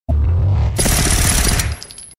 • RAPID GUNSHOTS.mp3
rapid_gunshots_ybs.wav